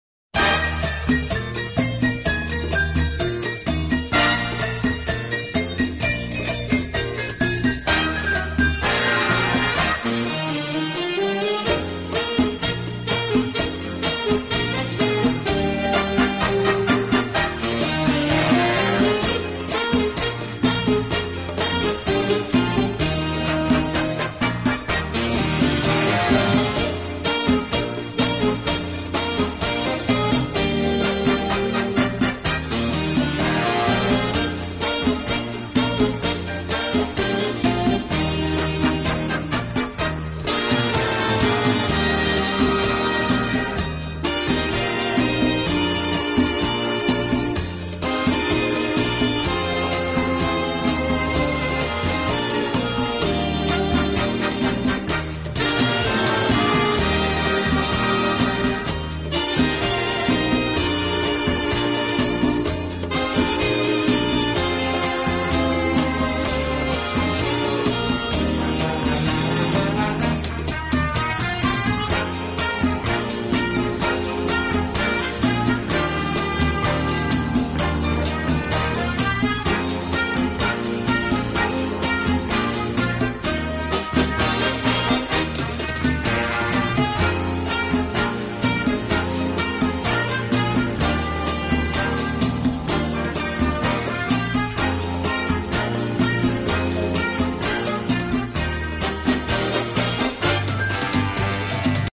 【轻音乐专辑】
的轻音乐团，以萨克斯管为主，曲目多为欢快的舞曲及流行歌曲改编曲。
轻快、柔和、优美，带有浓郁的爵士风味。